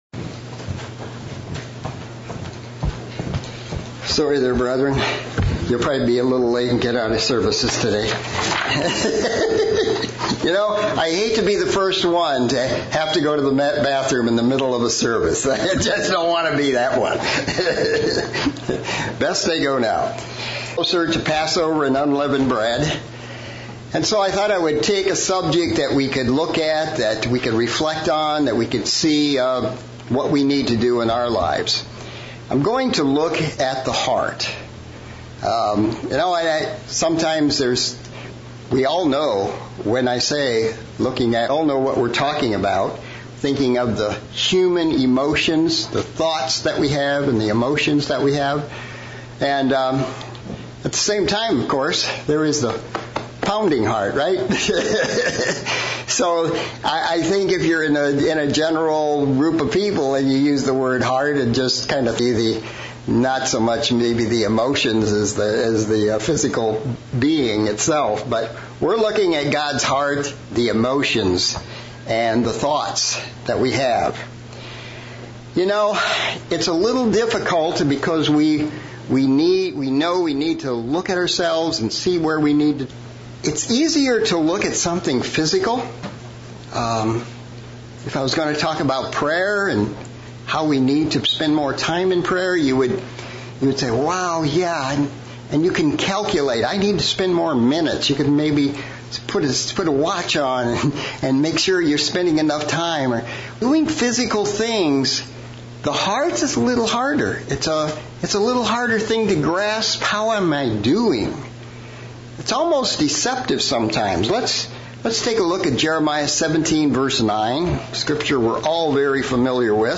Sermon looking at the Subject of the Heart from 3 distinct points of view